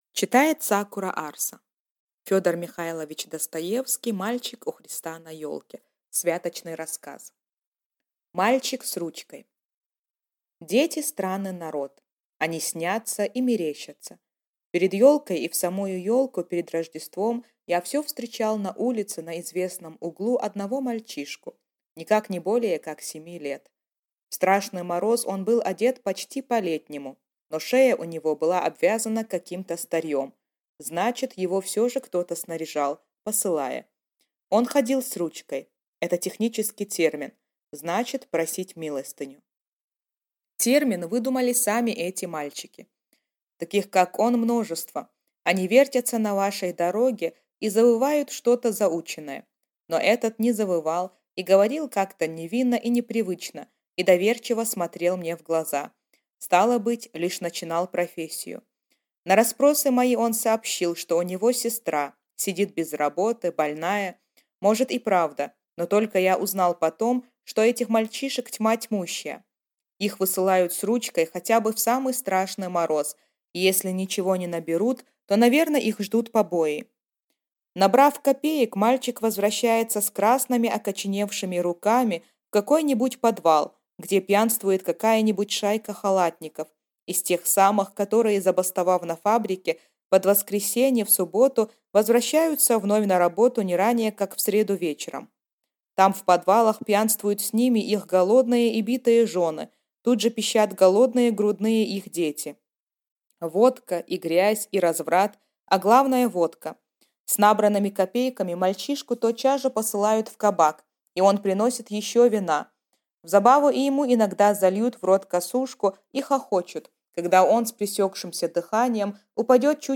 Аудиокнига Мальчик у Христа на елке | Библиотека аудиокниг